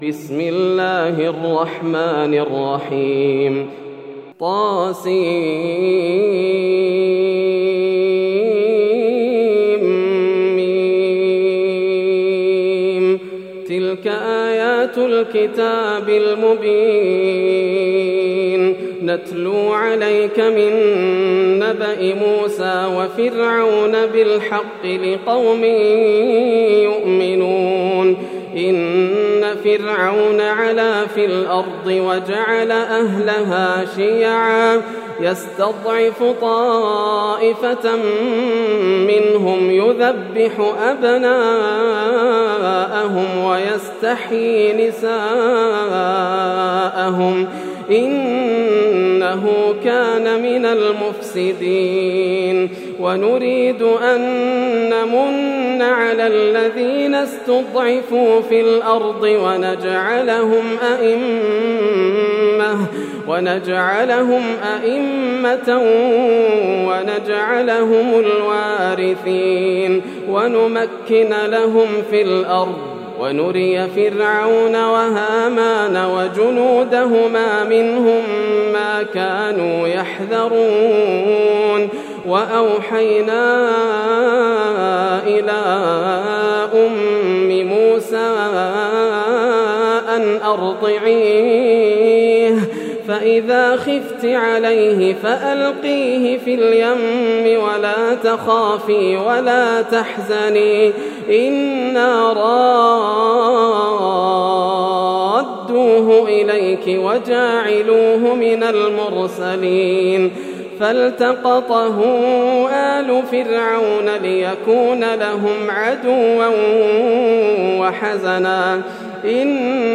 سورة القصص > السور المكتملة > رمضان 1431هـ > التراويح - تلاوات ياسر الدوسري